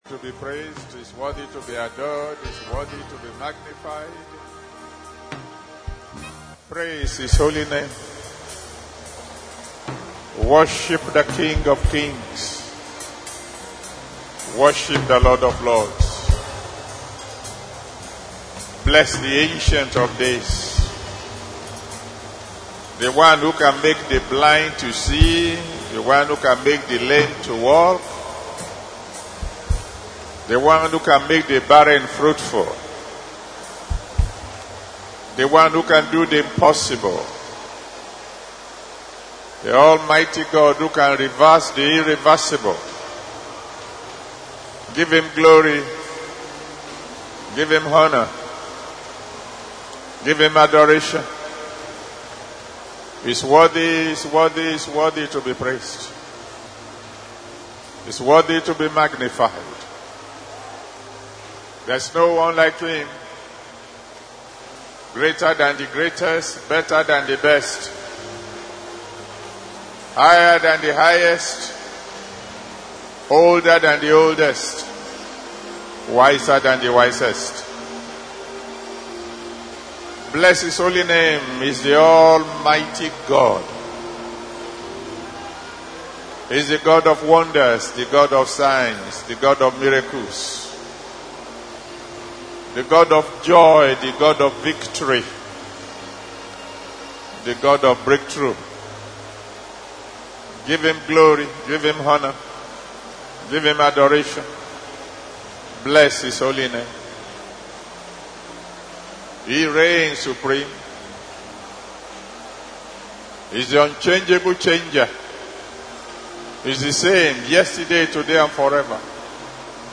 This is yet another powerful message by Pastor E.A Adeboye, the General Overseer, Redeemed Christian Church of God worldwide.